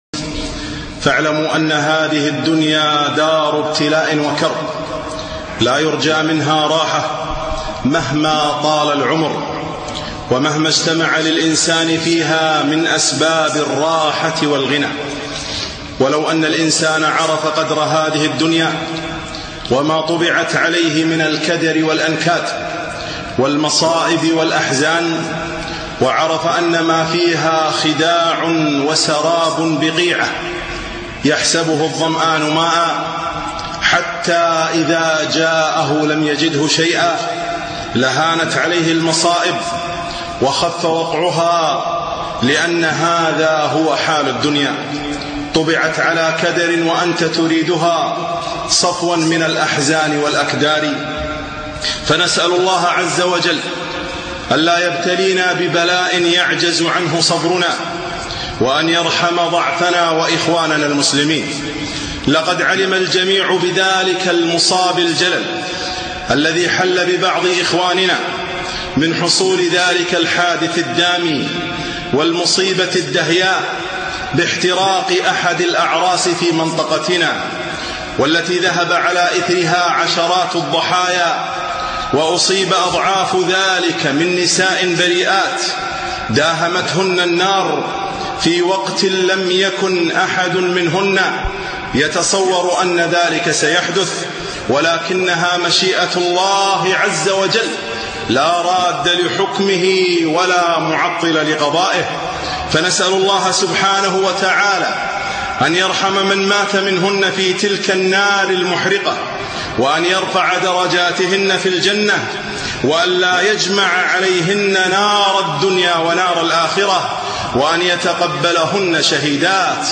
خطبة - تسلية أهل المصائب